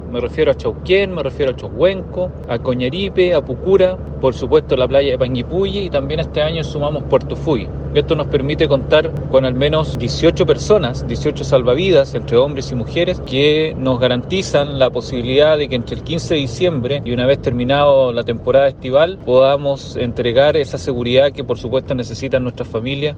El alcalde de Panguipulli, Pedro Burgos, señaló a Radio Bío Bío que habrán seis playas habilitadas, sumándose por primera vez Puerto Fuy, con un despliegue -en total- de 18 salvavidas.